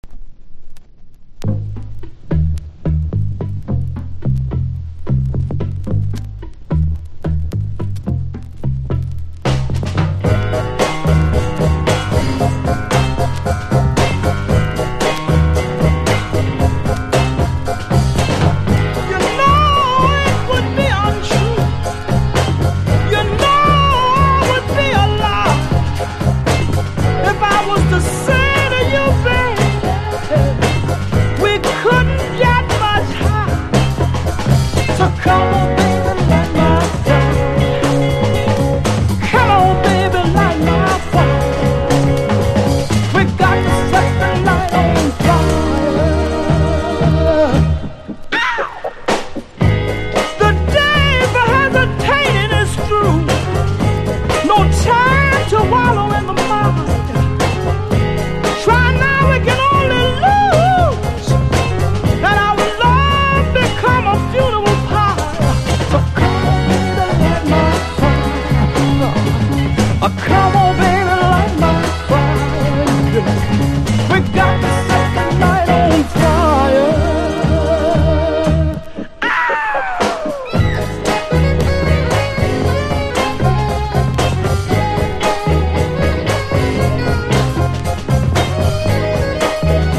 ソウルの名曲カバー集。